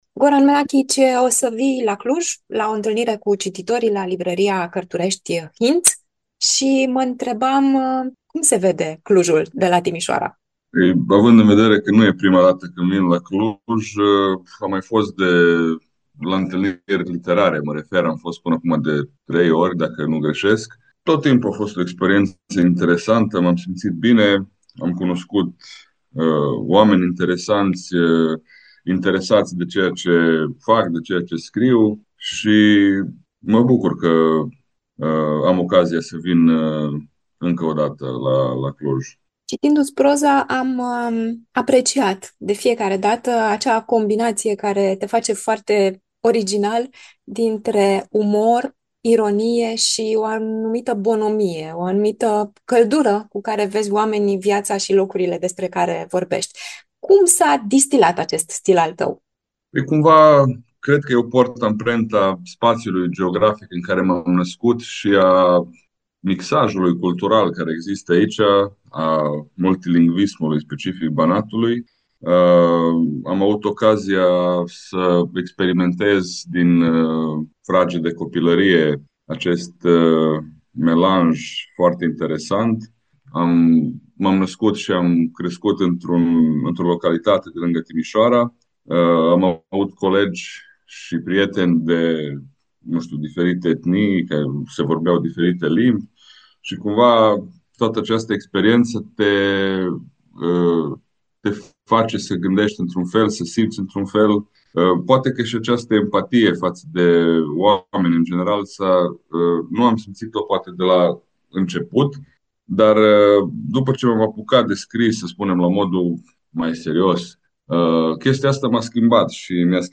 scurt interviu pentru Radio Cluj